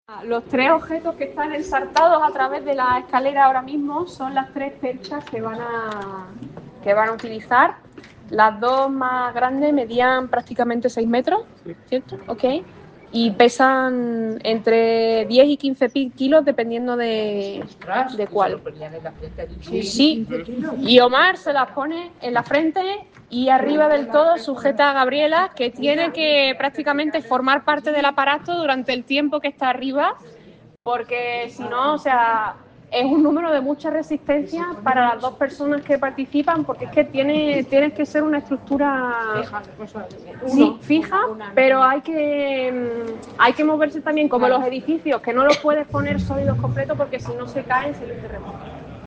describiendo-utiles-a-usar-por-equilibristas-creo-mp3